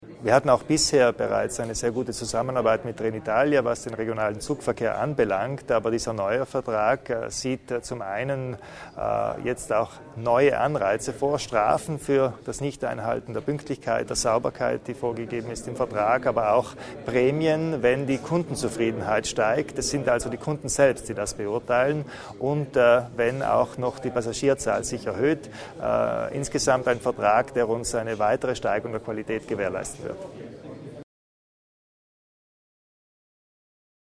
Landeshauptmann Kompatscher zur Bedeutung des neuen Dienstleistungsvertrags mit Trenitalia